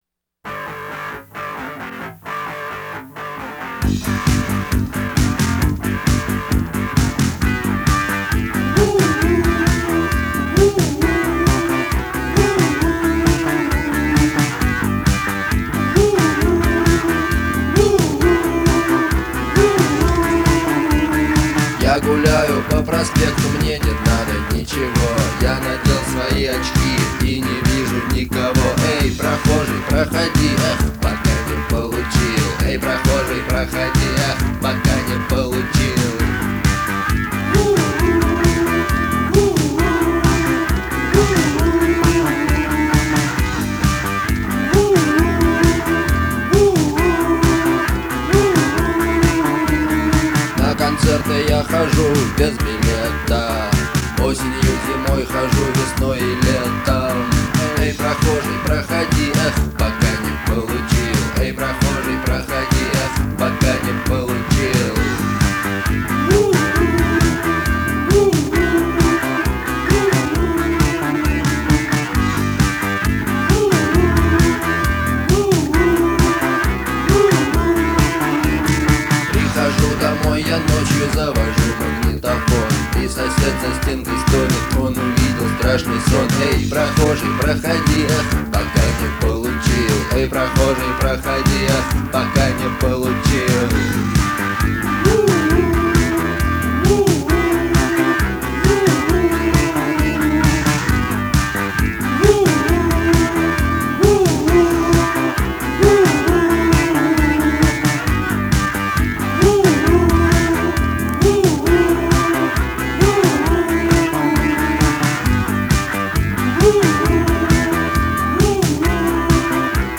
это яркий пример постпанка с меланхоличным настроением.